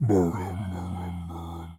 Audio / SFX / Characters / Voices / LegendaryChef / LegendaryChef_09.wav